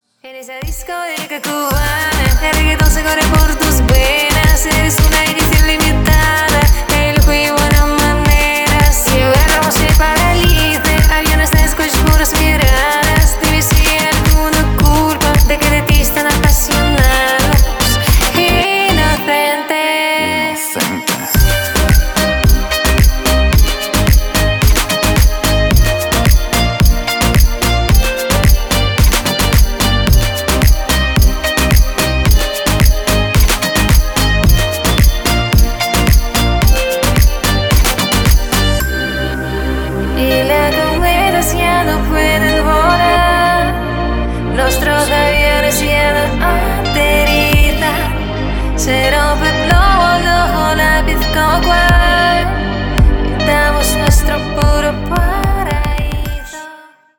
• Качество: 320, Stereo
заводные
Dance Pop
tropical house
красивый женский голос
легкие
теплые